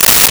Paper Tear 01
Paper Tear 01.wav